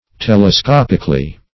Telescopically \Tel`e*scop"ic*al*ly\, adv.